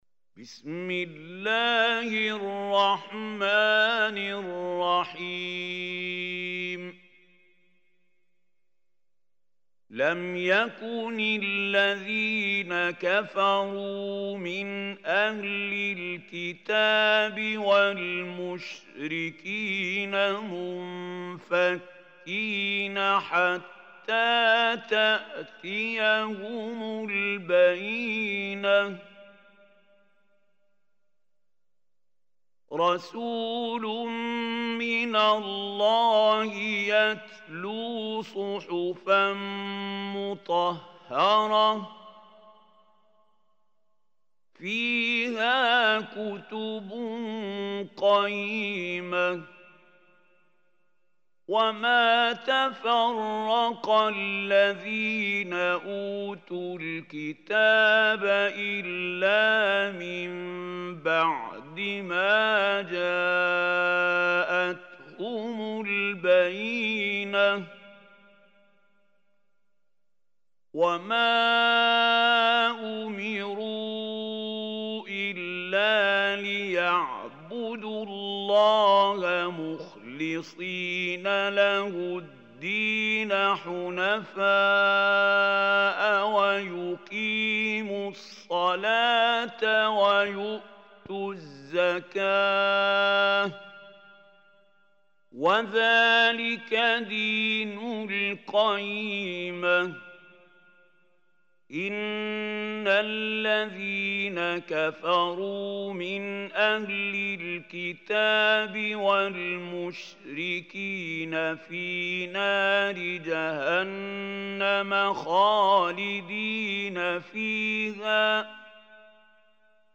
Surah Bayyinah Recitation Mahmoud Khalil Hussary
Surah al-Bayyinah is 98 surah of Holy Quran. Listen or play online mp3 tilawat/ recitation in arabic in the beautiful voice of Sheikh Mahmoud Khalil Hussary.